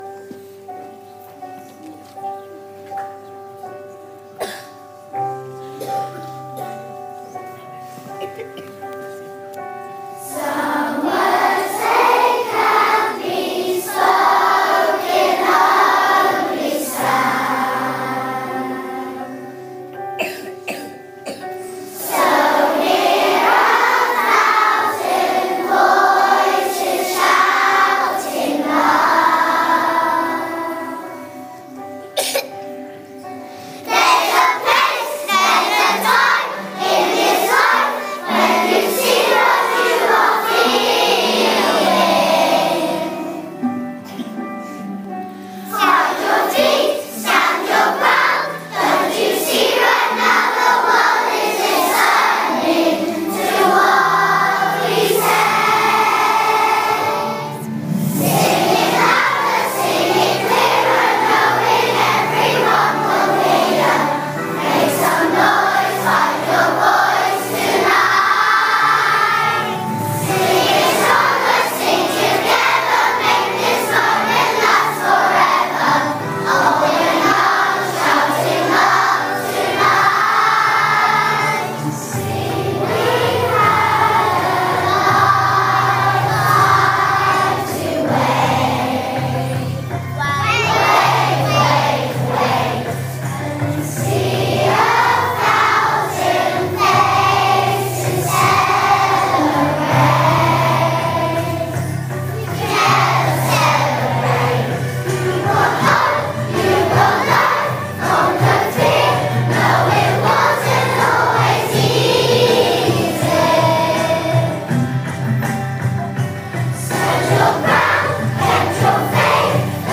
We are a Singing School!
We also use singing as an opportunity to bring our two schools together, using the wonders of technology to sing songs together over two sites.